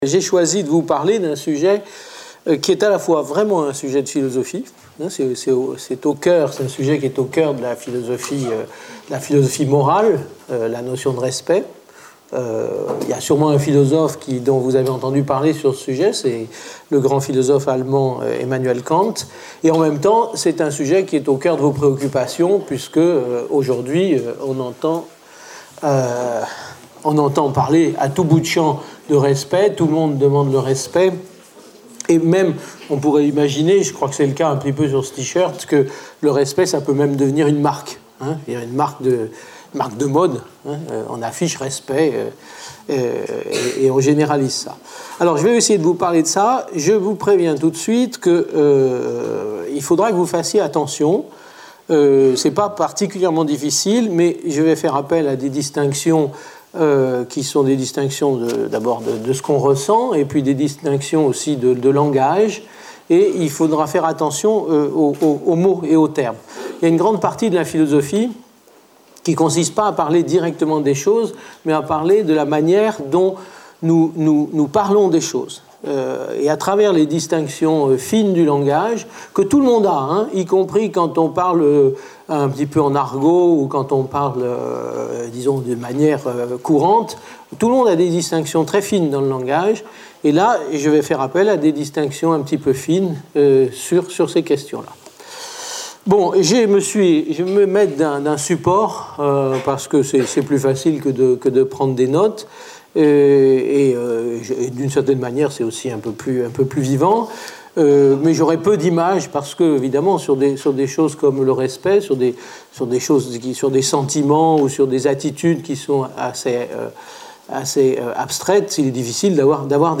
Une conférence de l'UTLS au lycée avec Yves Michaud Lycée Jean Macé